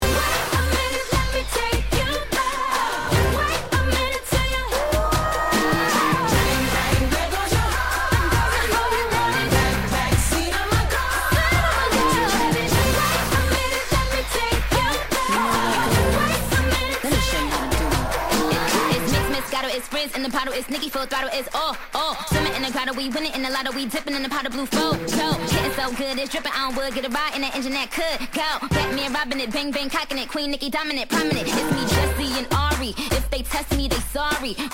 これも正に今をトキメクパーティーチューン!!
Tag       HIP HOP HIP HOP